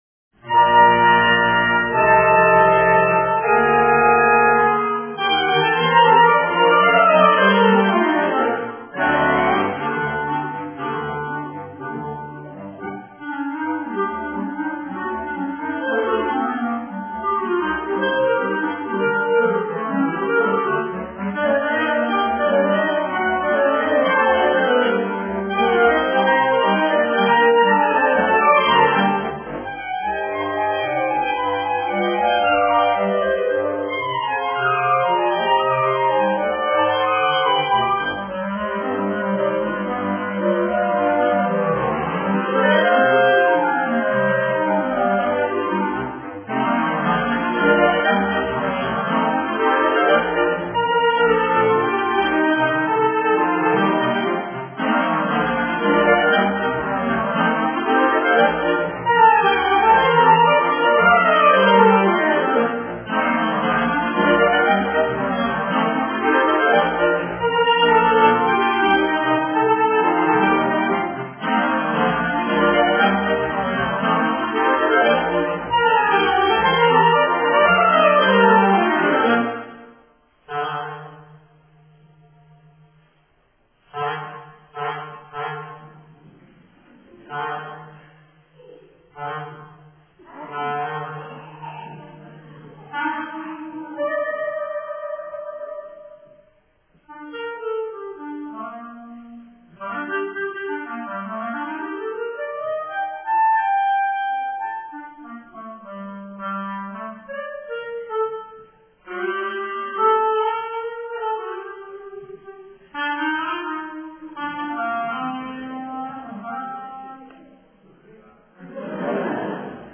Solo cla
Alto cla
Bass cla